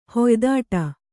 ♪ hoydāṭa